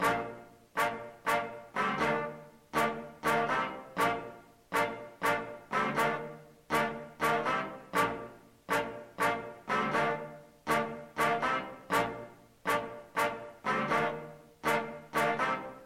敲击式灵魂号角
描述：8个无缝小节的小铜管合奏，演奏一个打击性的riff。
标签： 121 bpm Soul Loops Brass Loops 2.67 MB wav Key : A
声道立体声